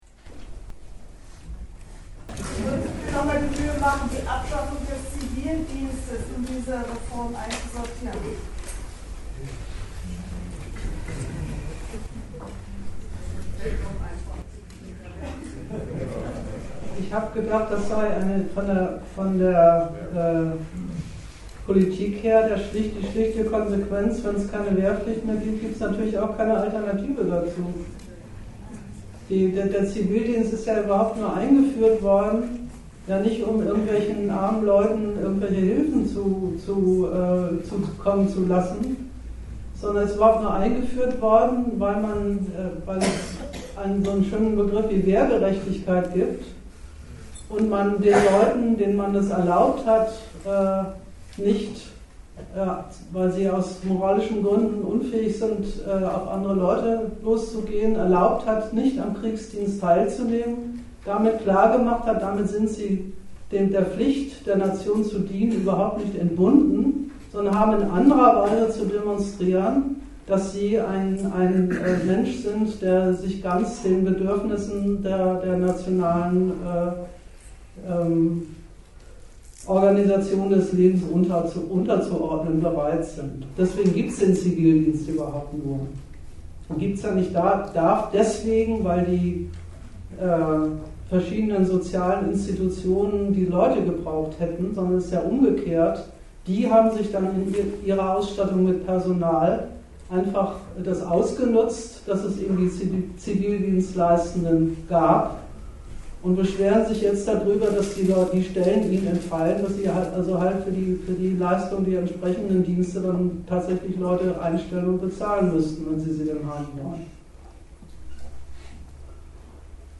Ort Bremen
Dozent Gastreferenten der Zeitschrift GegenStandpunkt